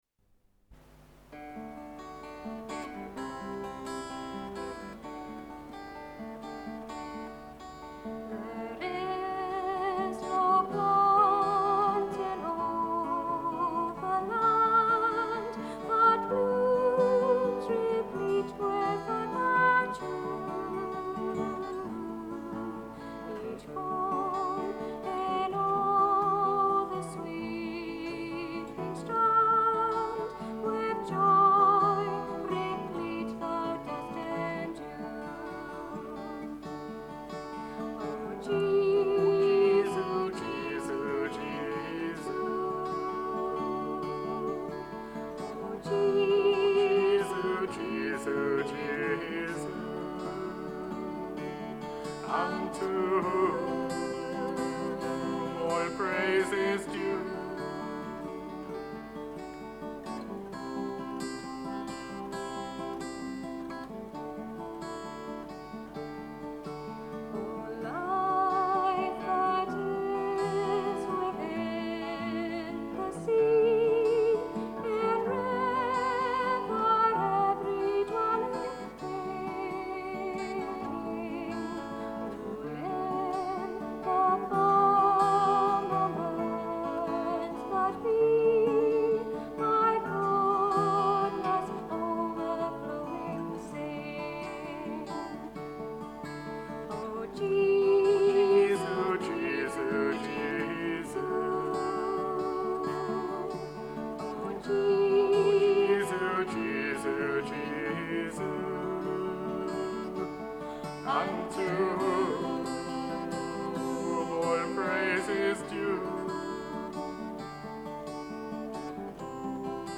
An anthem reflecting on the beauty of Christ in nature. Two voices with guitar or piano accompaniment.
Recording by "Trinity", Holy Trinity Episcopal Church, Stirling